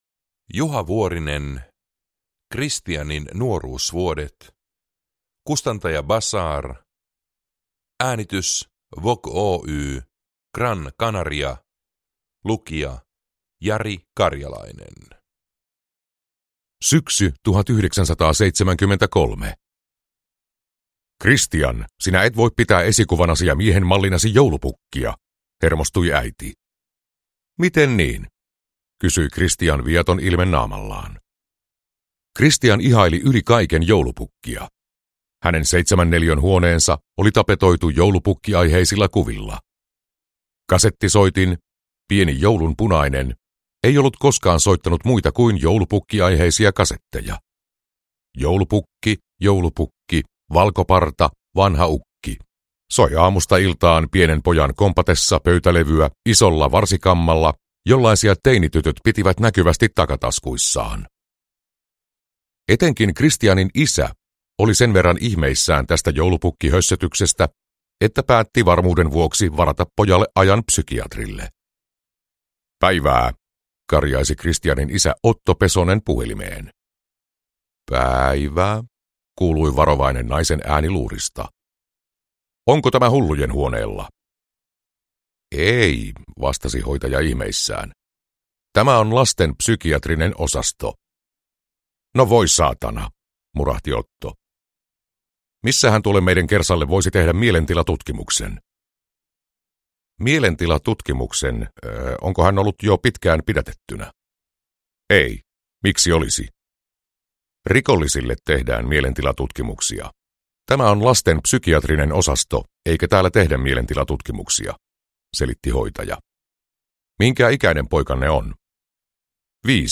Kristianin nuoruusvuodet – Ljudbok